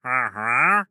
Minecraft Version Minecraft Version latest Latest Release | Latest Snapshot latest / assets / minecraft / sounds / mob / wandering_trader / haggle2.ogg Compare With Compare With Latest Release | Latest Snapshot
haggle2.ogg